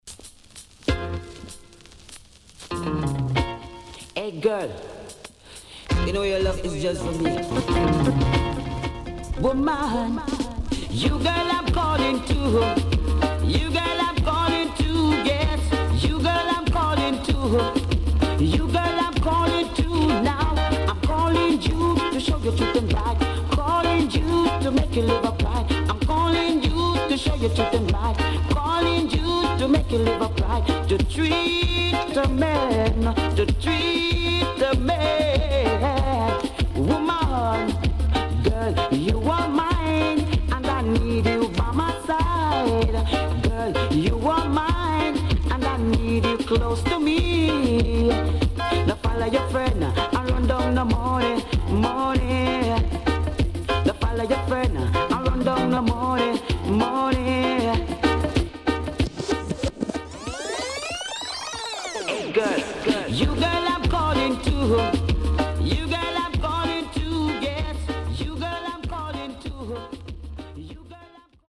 HOME > REISSUE USED [DANCEHALL]
Nice Vocal.W-Side Good.Good Condition